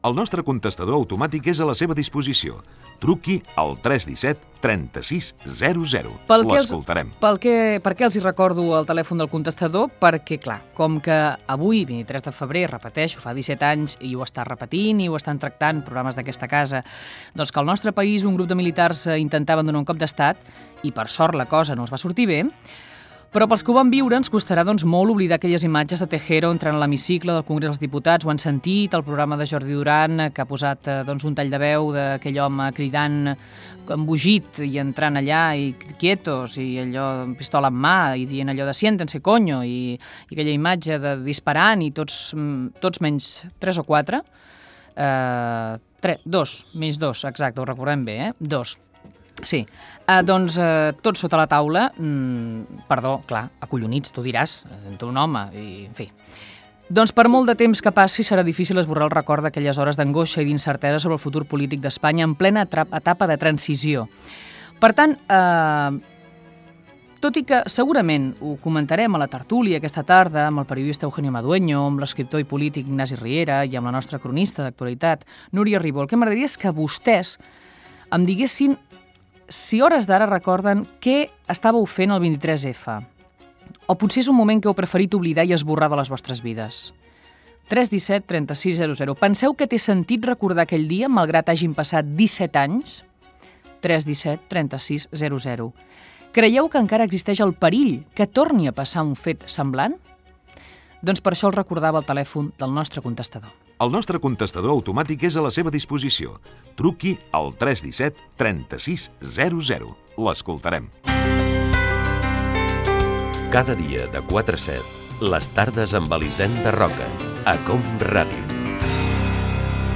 Entreteniment
FM
Fragment extret de l'arxiu sonor de COM Ràdio.